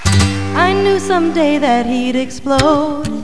folk group